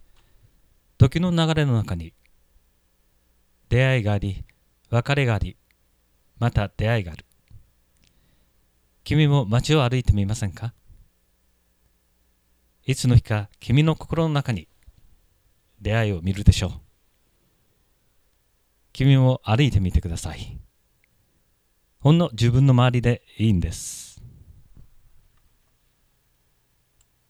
朗読